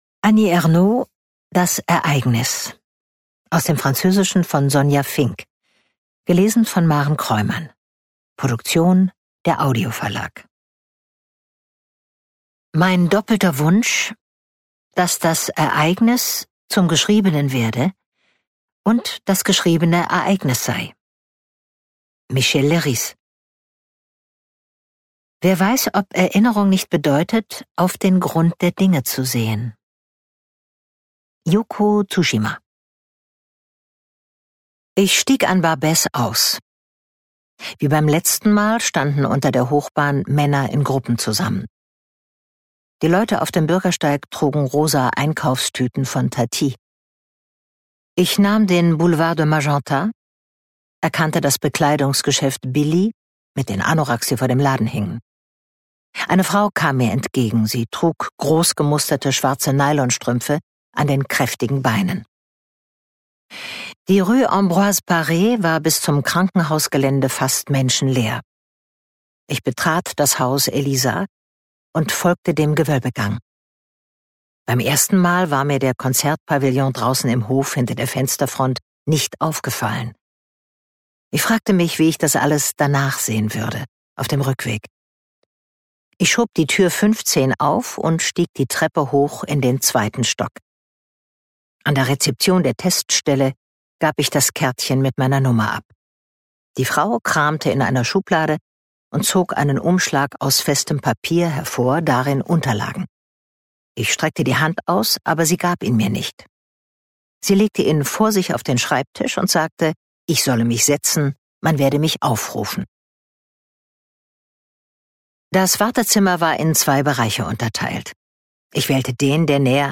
Maren Kroymann (Sprecher)